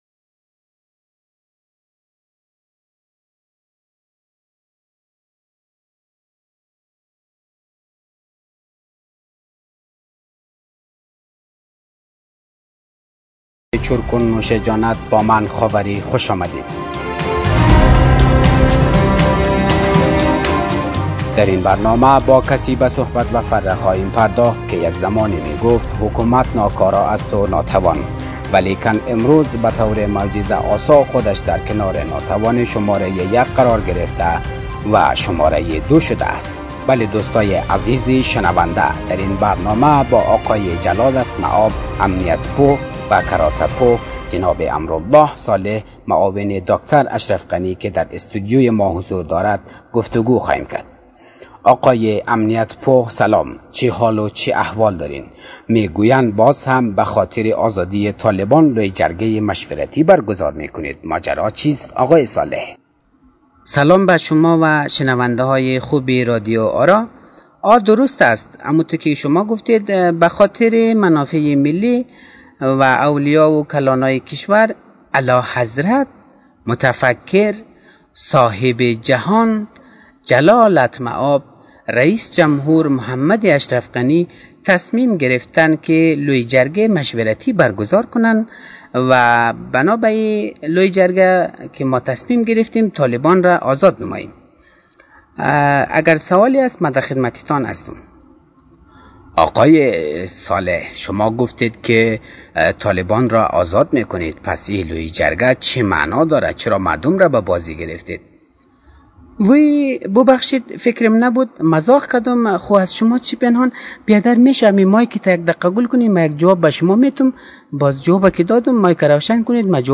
پادکست «طنزآرا» با اجرای تیمی از استنداپ کمیدین ها و طنزپرداز های خوب افغانستانی تهیه می شود.